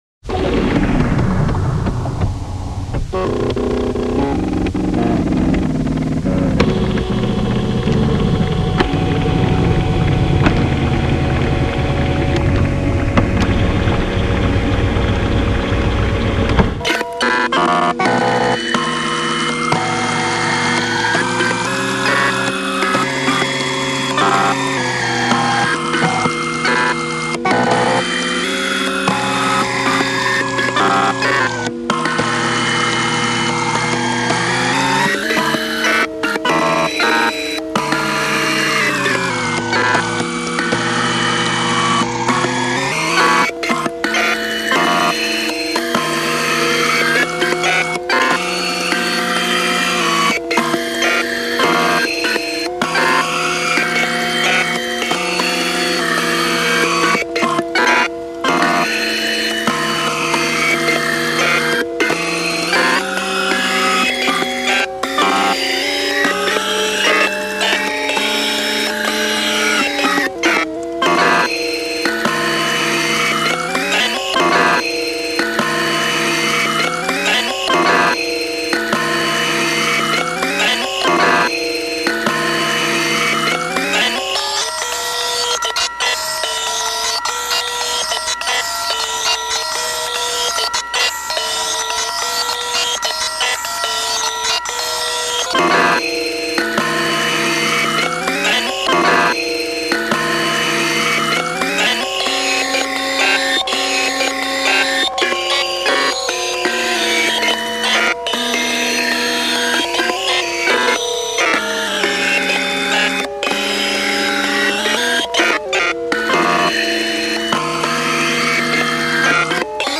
These were then scanned and the noises made by the scanner preserved as audio files, which the composer manipulated in the computer to build tracks.